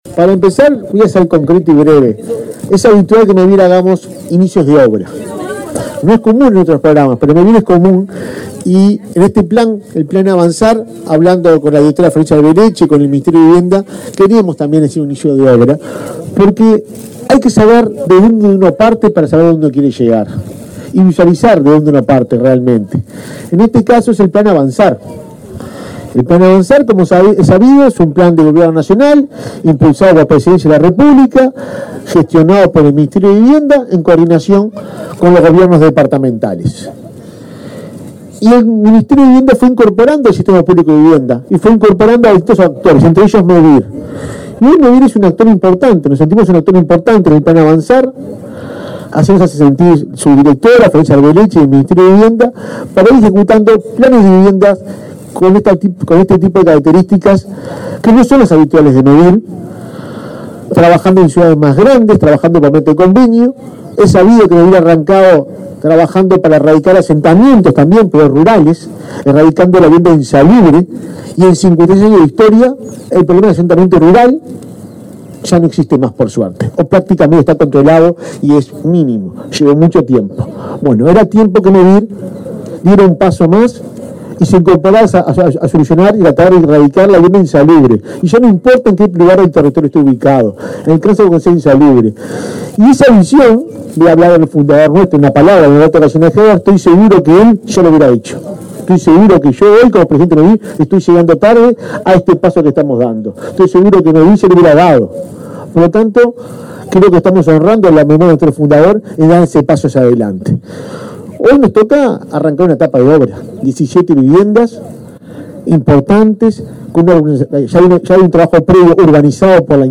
Palabra de autoridades en acto del Ministerio de Vivienda y Mevir en Fray Bentos